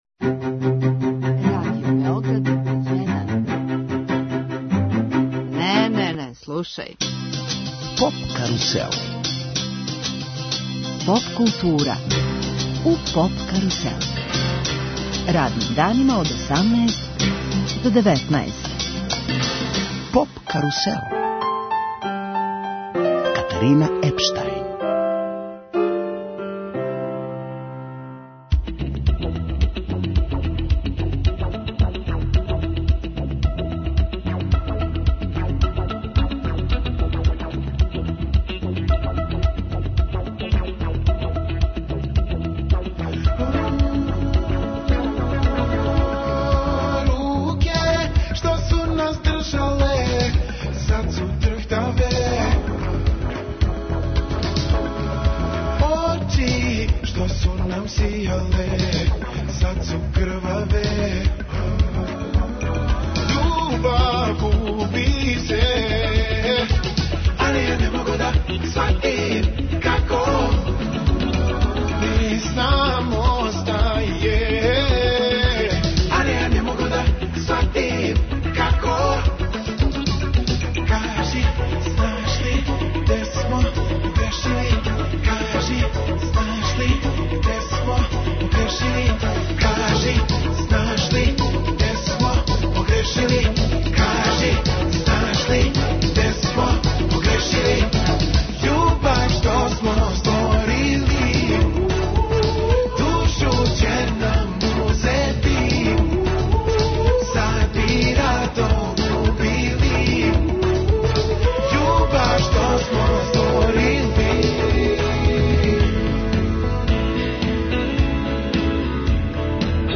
Поводом Шабачког летњег фестивала, Поп карусел емитује се, ексклузивно из овога града.